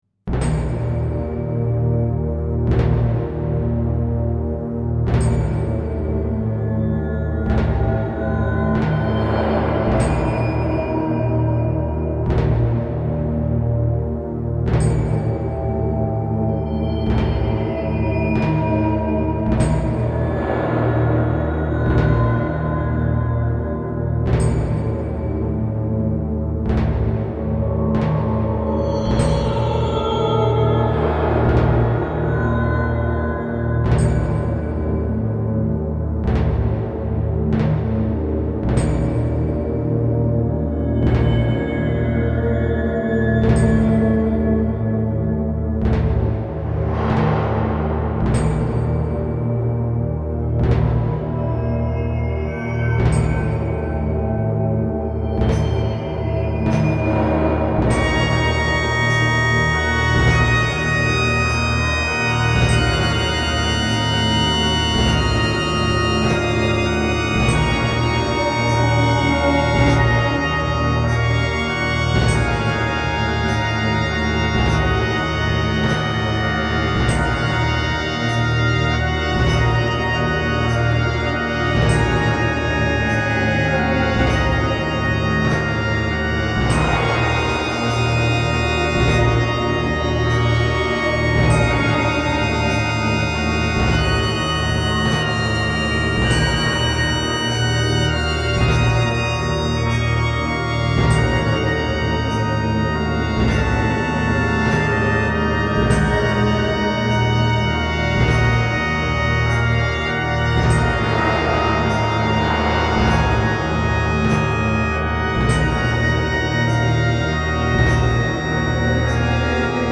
Dark ambient